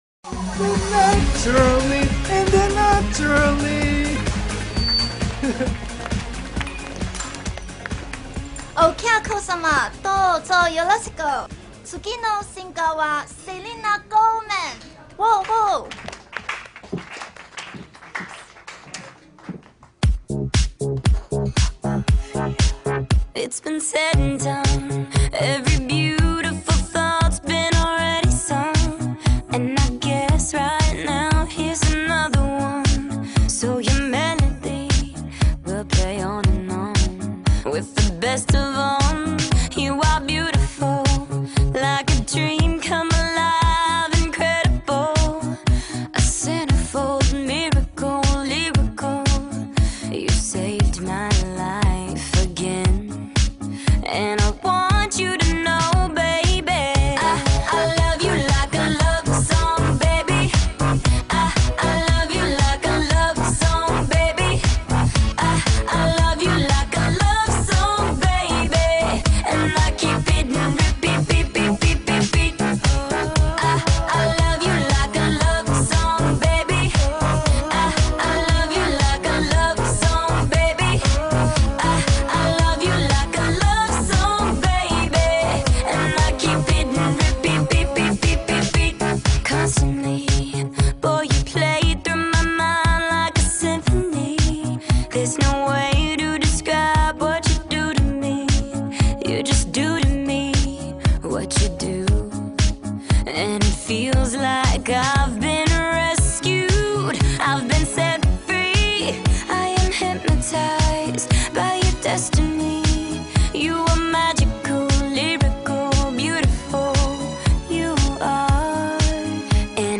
Pop, Disco, Electro